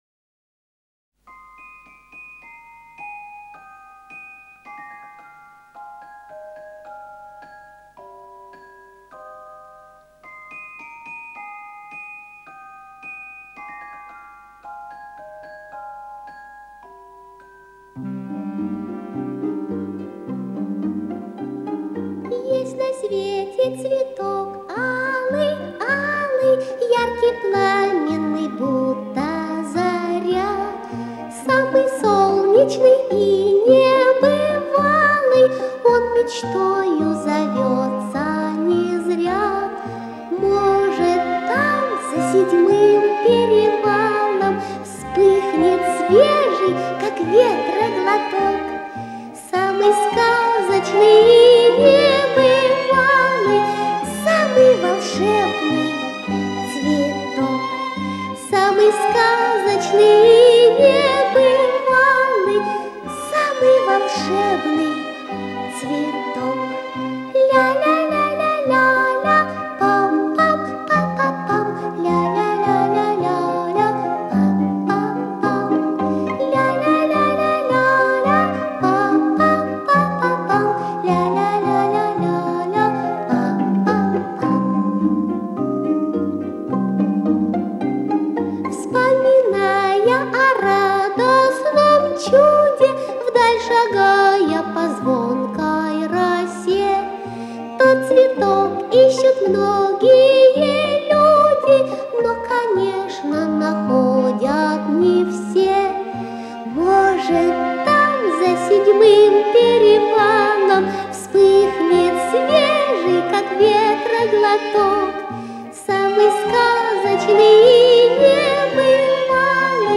песня для детей, можно использовать в занятии к празднику 8 марта "Букет для любимой бабушки"